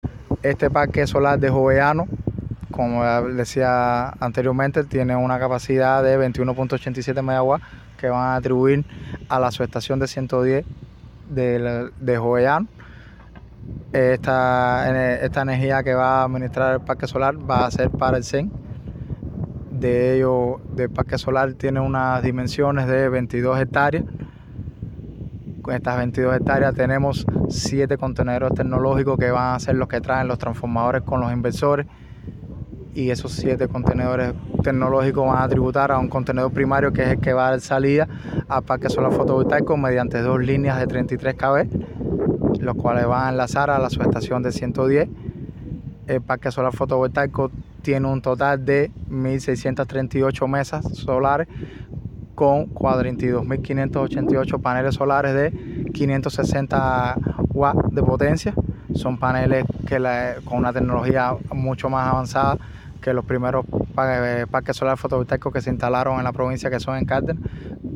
De las características del parque, la tecnología que se pretende instalar, así como de los beneficios en materia de aporte energético también detalló el joven especialista: